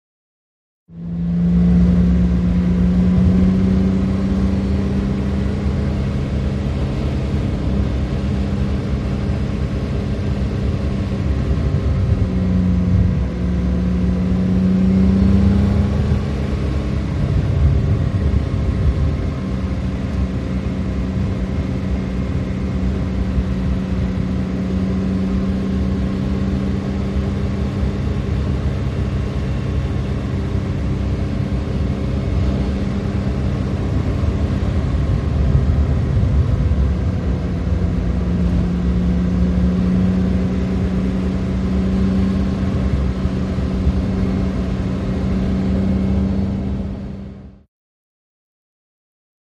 VEHICLES ASTON MARTIN: INT: Constant run in one gear.